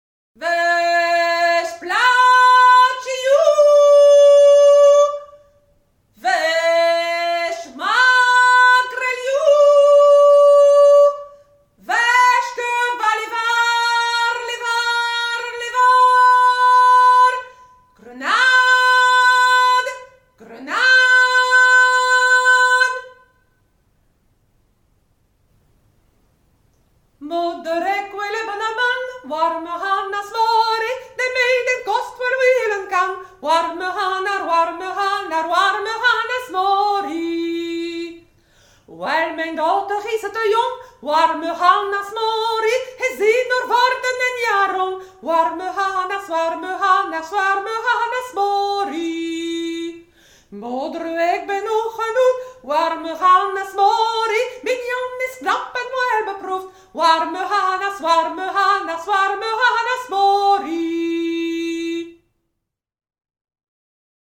Chants des marins
Pièce musicale éditée